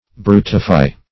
Search Result for " brutify" : The Collaborative International Dictionary of English v.0.48: Brutify \Bru"ti*fy\, v. t. [imp.